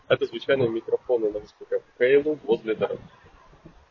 Встроенный срдений, особенно в шумных условиях.
В шумных условиях:
haylou-s30-shumno.m4a